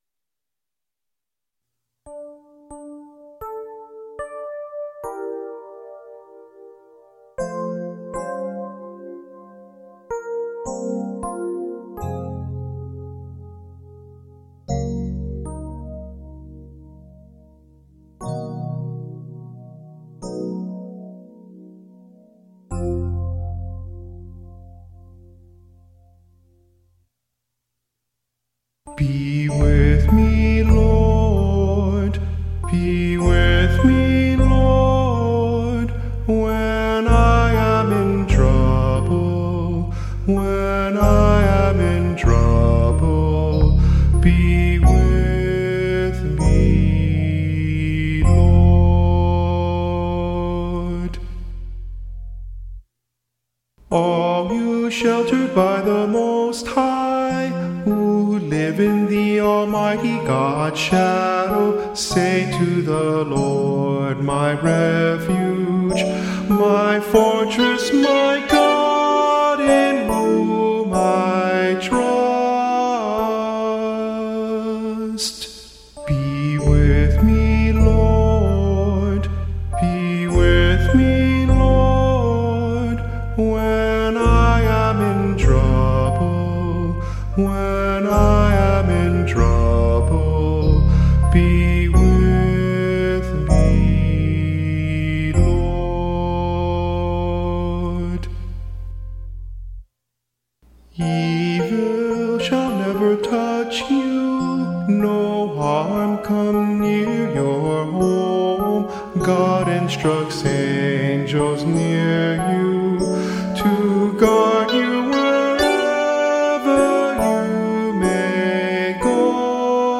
Instrumental | Downloadable